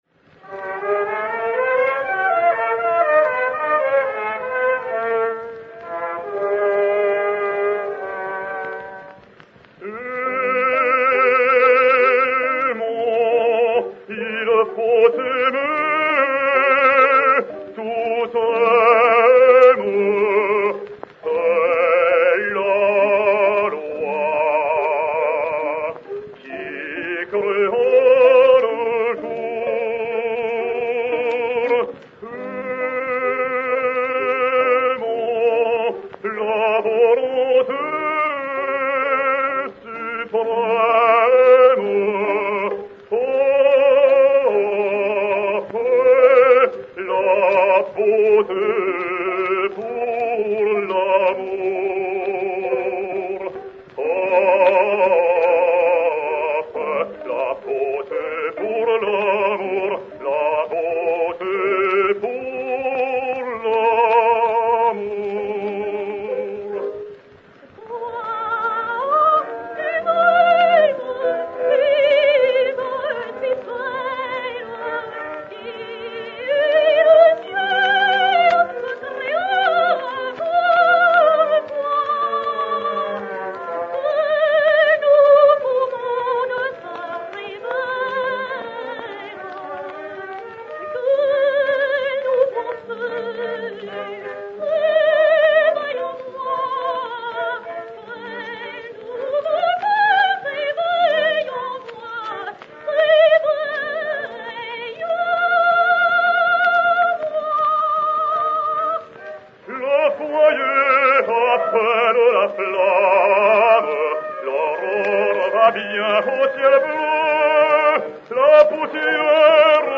Duo "Aimons, il faut aimer"
et Orchestre
Cylindre Edison 17128, enr. à Paris en octobre 1911